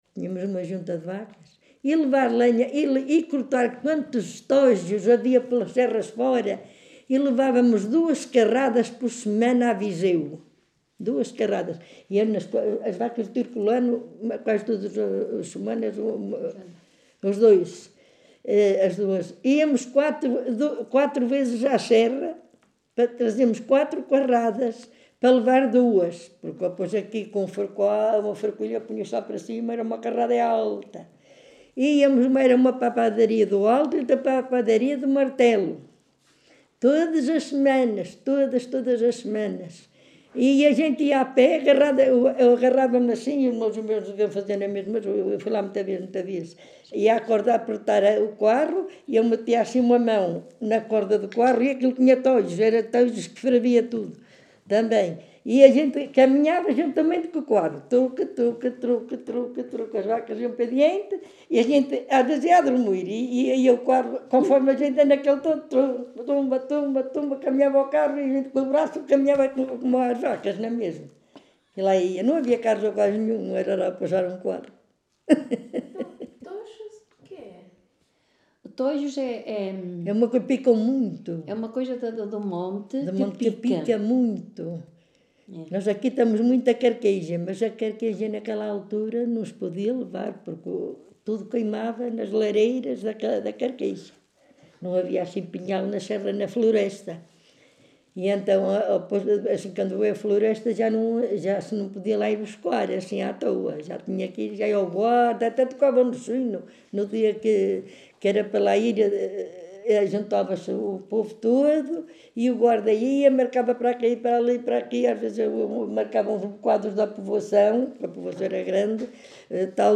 Várzea de Calde, primavera de 2019. Registo sonoro integrado numa série de gravações realizadas para o projeto Viseu Rural 2.0 e para o Arquivo da Memória de Várzea de Calde, cujo tema principal são diversos usos e conhecimentos sobre plantas: medicina, culinária, construção de ferramentas...
Tipo de Prática: Inquérito Oral